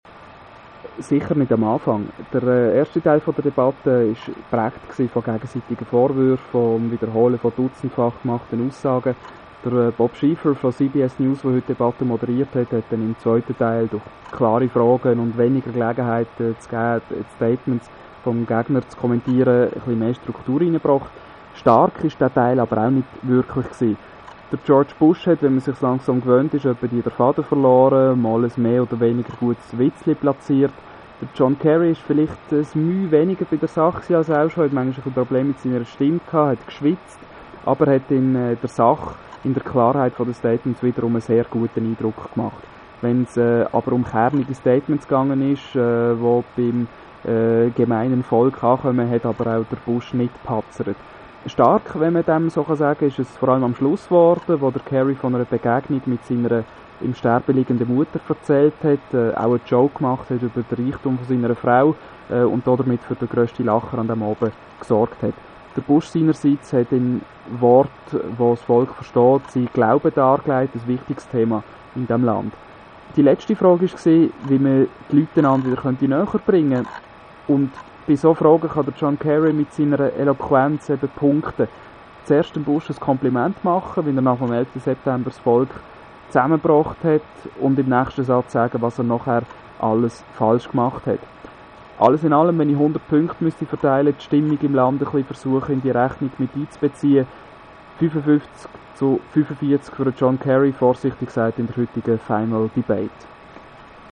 Und dann also die Final Presidential Debate - verfolgt in Bennington, Vermont. meine Eindrücke gleich im O-Ton, so wie ich sie Radio ExtraBern geschildert habe:
Diese Quotes wurden natürlich auseinandergeschnipselt, das wäre alles viiiel zu viel.